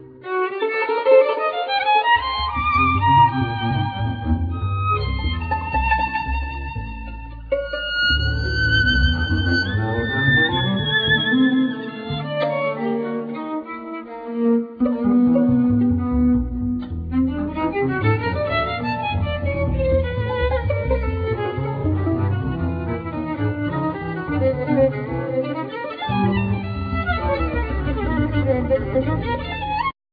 Piano
Violin
Bass